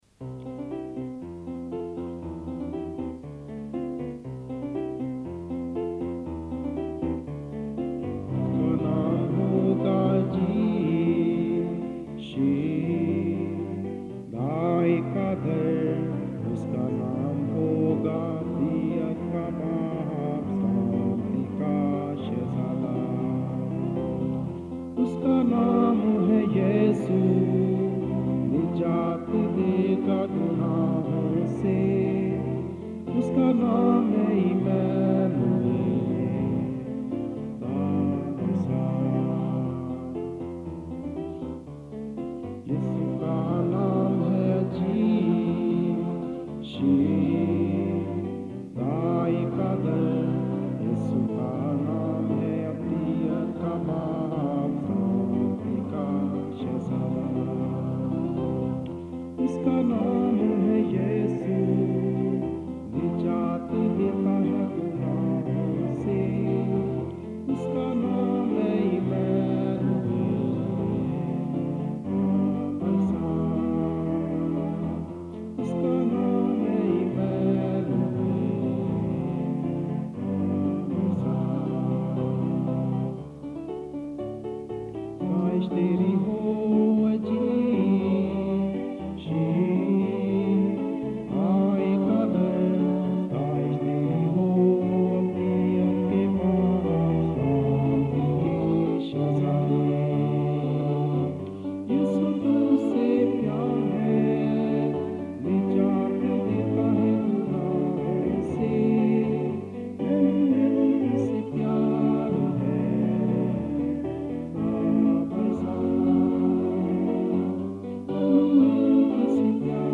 Hear this translation (MP3 with accompaniment)
Hear this translation (MP3 - vocal only)